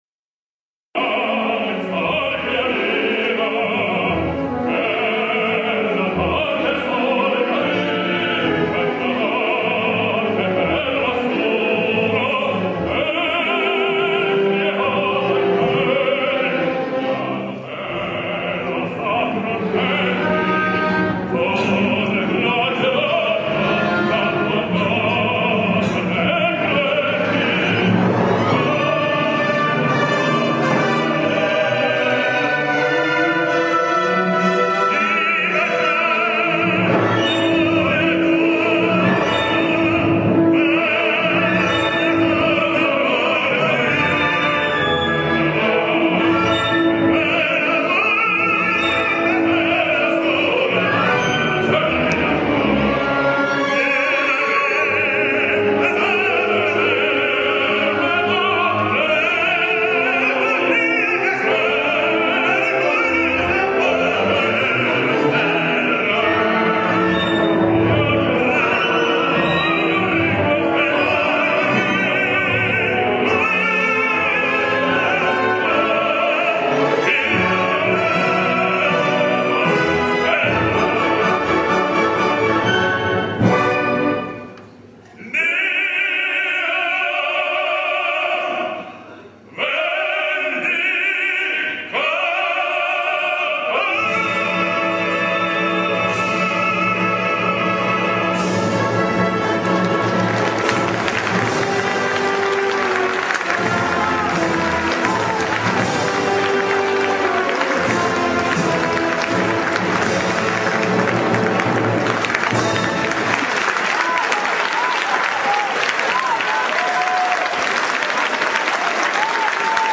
While Eastern Europe in general and Russia in particular have beyond doubt maintained quality in operatic singing for a much longer time than the rest of the world (and in some cases, they've maintained it even up to our days), it's the proof that there is no such thing as a Promised Land of opera, not even in Russia, if a positively horrible shouter and pusher like Osipov could become of the country's most famous tenors...
Vjacheslav Osipov singsOtello: